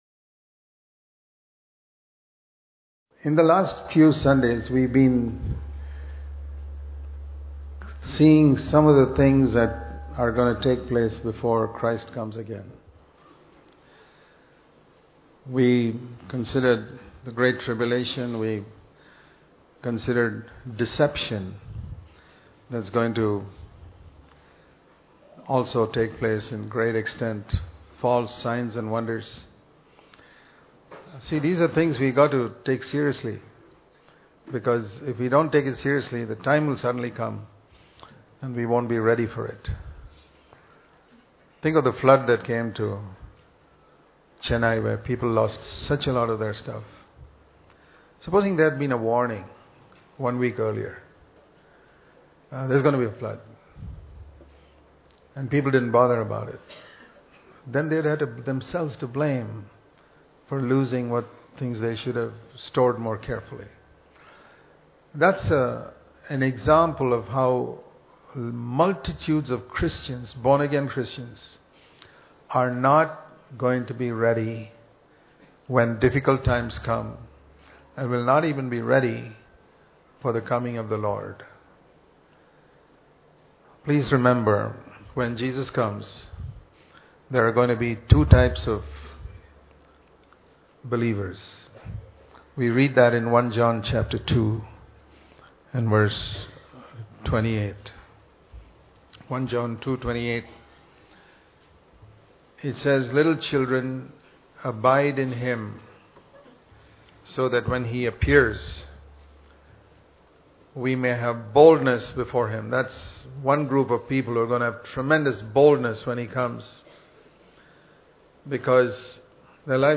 at Christian Fellowship Church, India